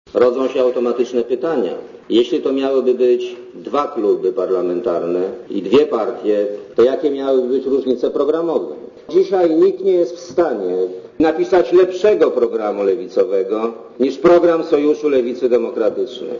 Sądząc po biegu ostatnich zdarzeń nie można wykluczyć, że w SLD dojdzie do rozłamu - przyznał premier Leszek
Posłuchaj, co o rozłamie mówił Leszek Miller